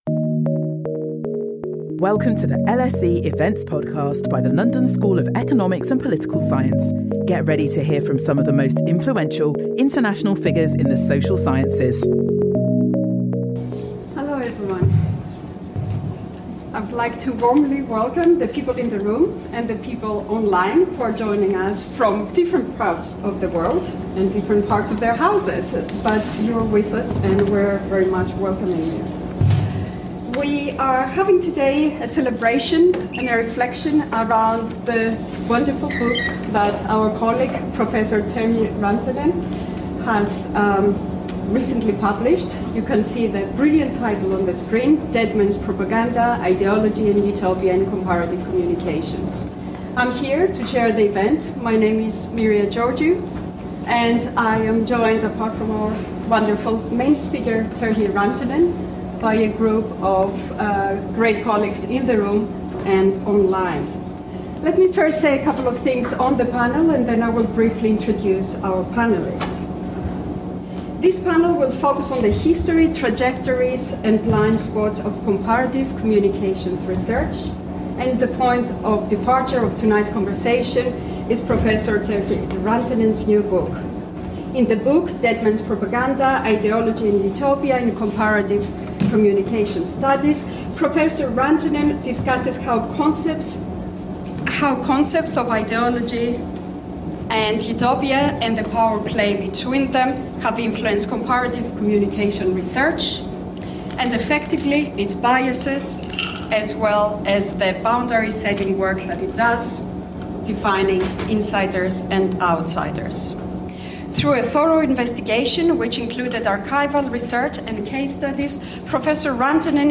this panel will examine how comparative communications research, from its very beginning, can be understood as governed by the Mannheimian concepts of ideology and utopia and the power play between them.